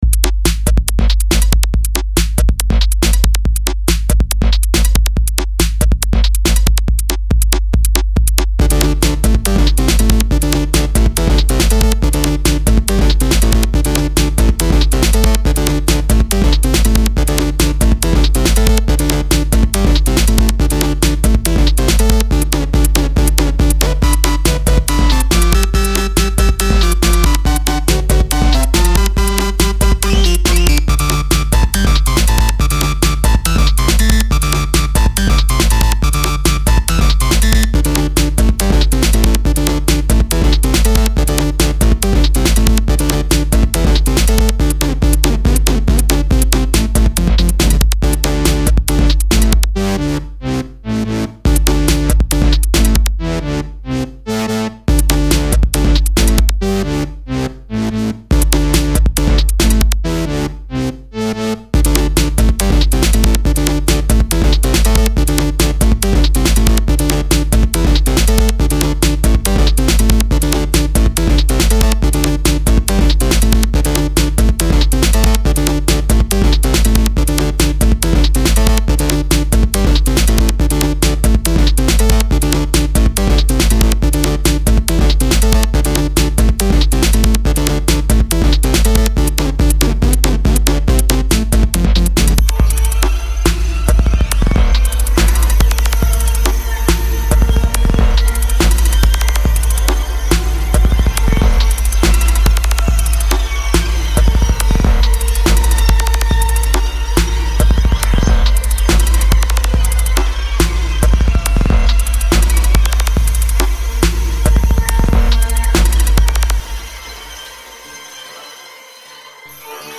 retroish electro